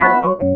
Key-organ-03_003.wav